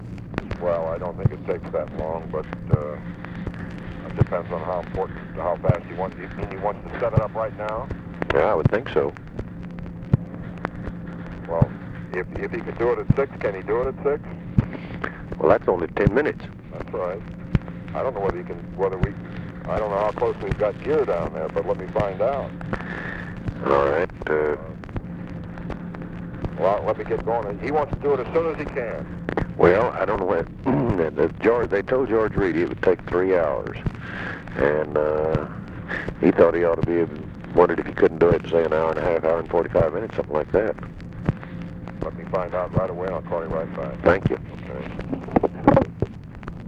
Conversation with WALTER JENKINS and (possibly) FRANK STANTON, April 22, 1964
Secret White House Tapes